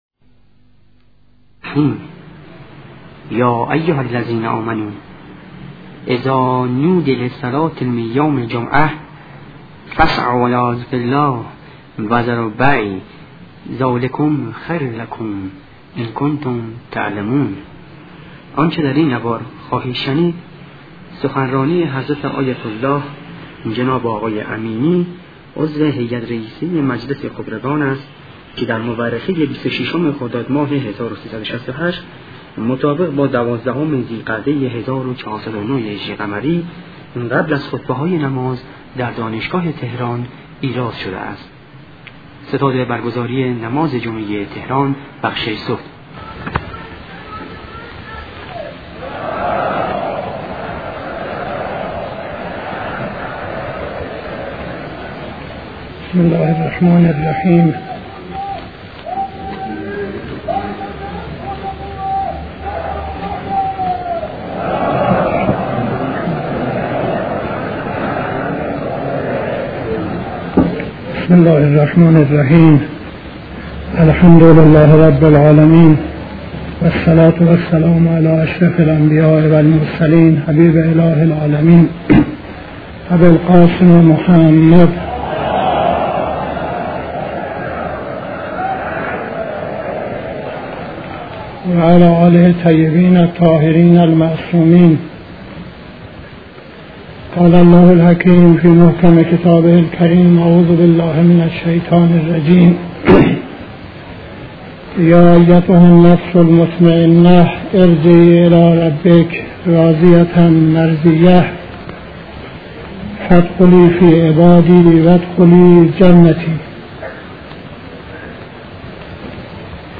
قبل از خطبه‌های نماز جمعه تهران 26-03-68